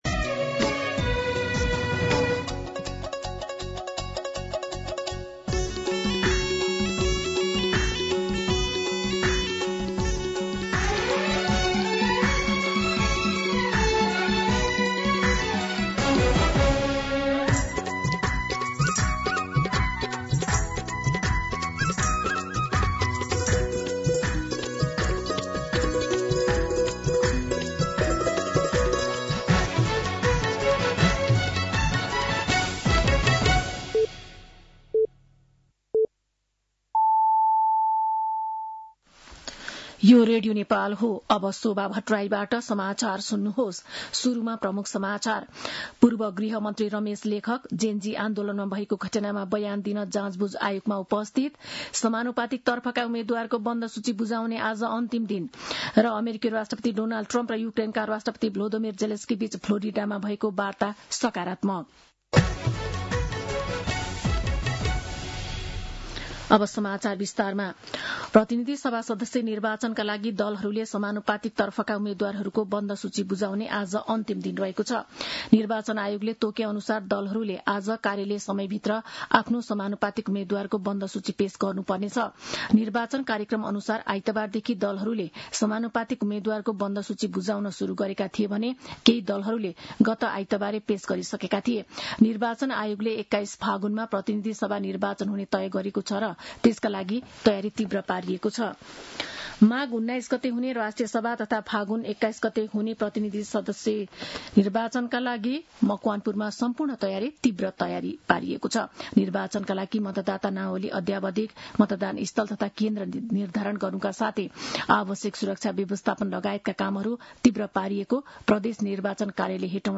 दिउँसो ३ बजेको नेपाली समाचार : १४ पुष , २०८२
3-pm-News-9-14.mp3